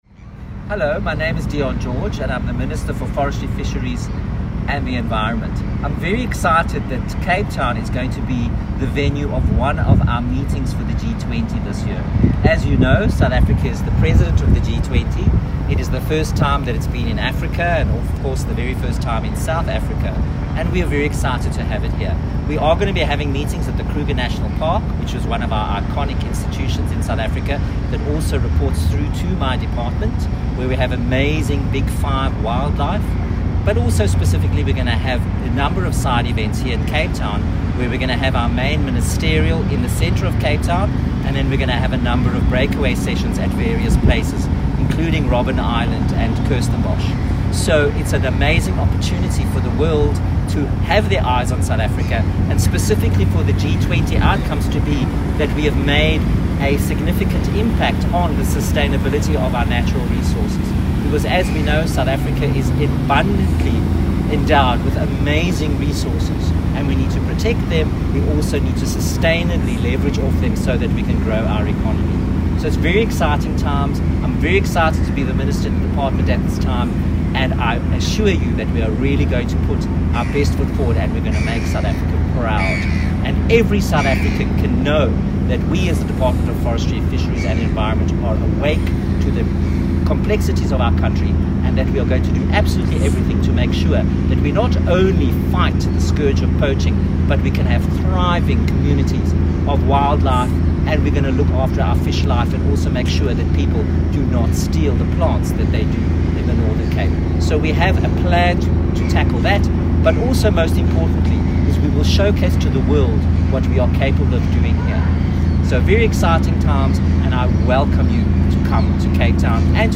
Audio | G20 ECSWG Message by Minister Dr Dion George.MP3